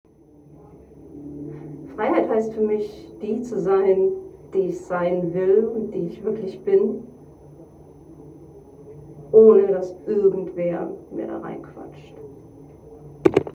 Standort der Erzählbox:
Computerspielemuseum @ Berlin